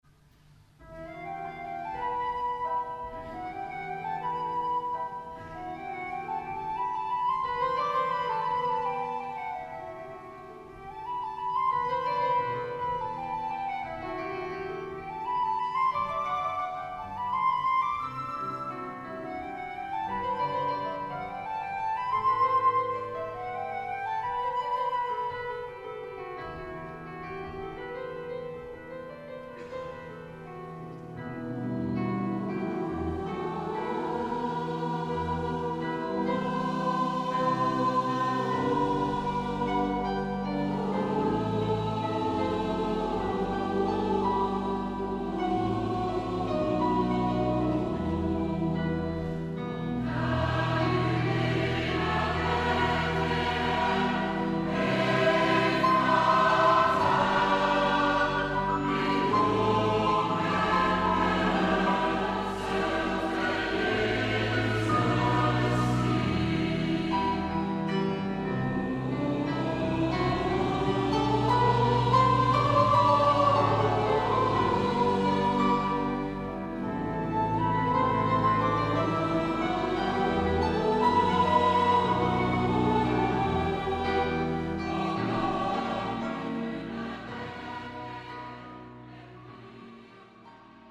Opnames Kerstconcert 2004